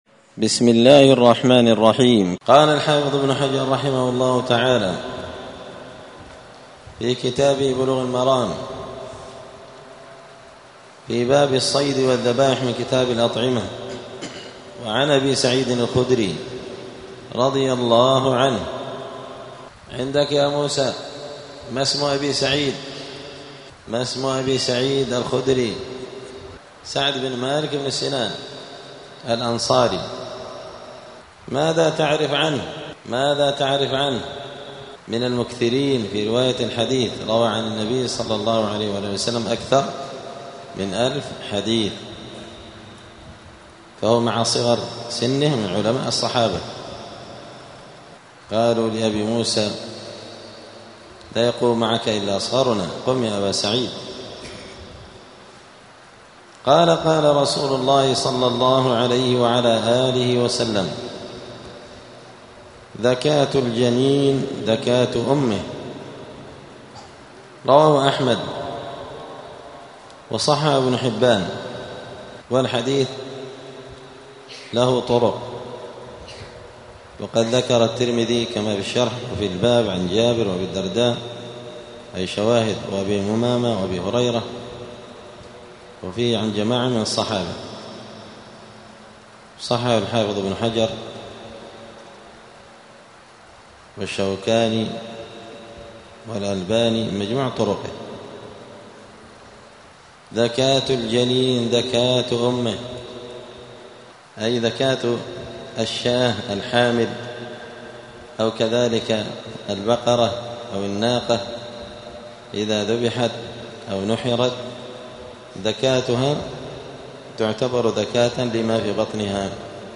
*الدرس الثامن عشر (18) {باب الصيد والذبائح ذكاة الجنين ذكاة أمه}*
دار الحديث السلفية بمسجد الفرقان قشن المهرة اليمن